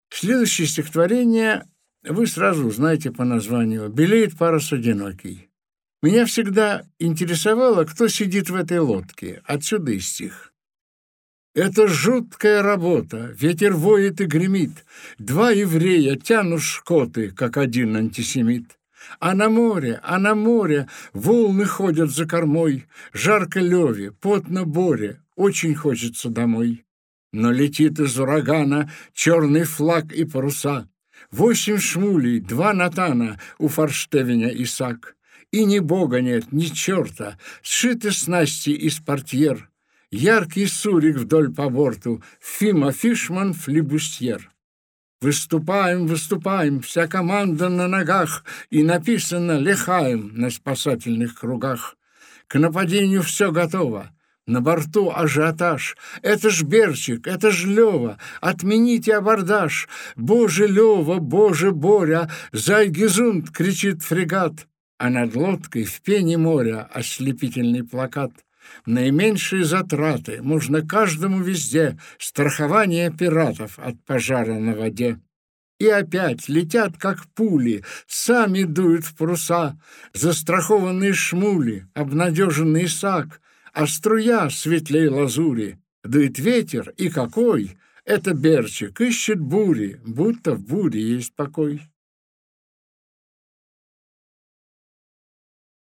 Аудиокнига Обгусевшие лебеди | Библиотека аудиокниг
Aудиокнига Обгусевшие лебеди Автор Игорь Губерман Читает аудиокнигу Игорь Губерман.